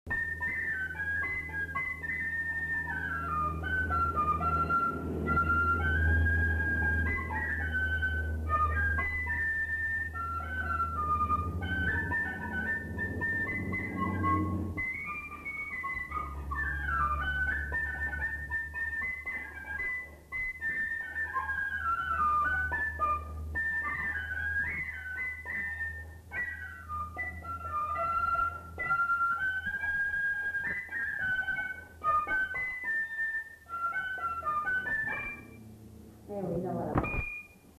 Lieu : Bazas
Genre : morceau instrumental
Instrument de musique : fifre
Danse : valse